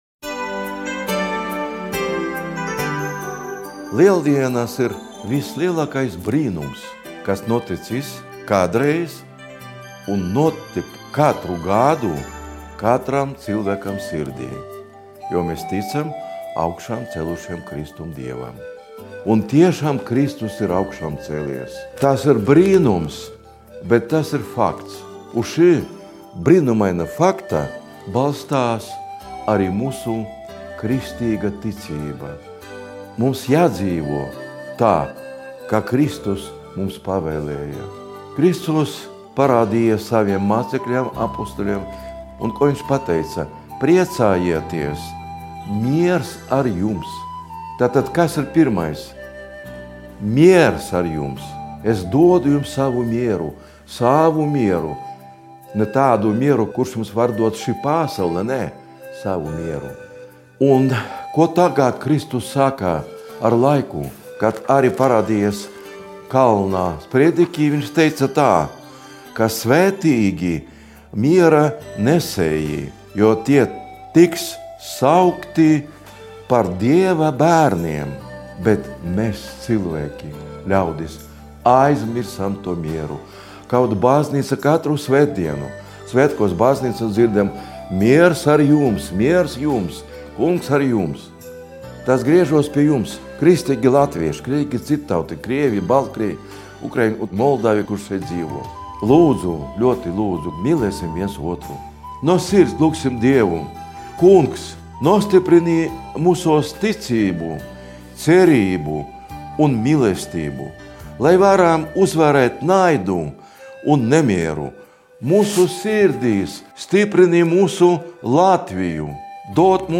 Latvijas Pareizticīgo baznīcas arhibīskapa metropolīta Aleksandra apsveikums Lieldienās